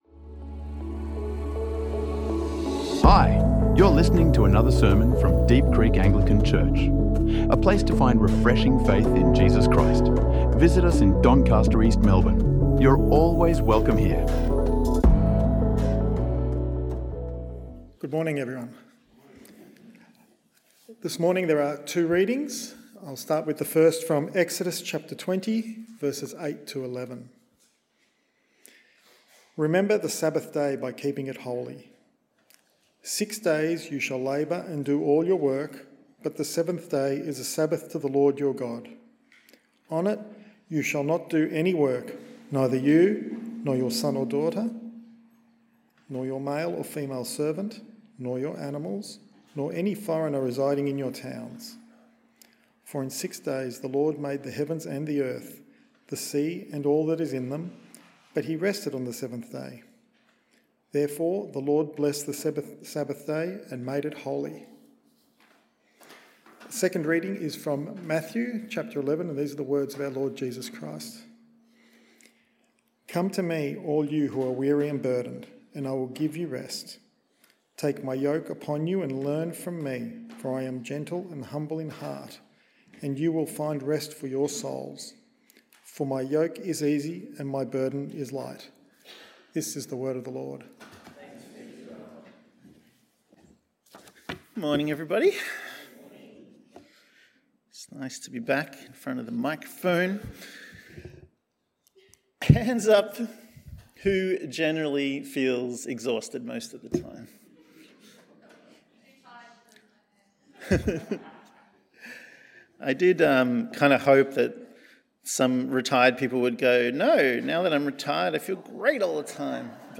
Sermon on Sabbath and rest, exploring its biblical story and offering practical ways to build a life-giving rhythm of rest in Jesus.